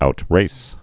(out-rās)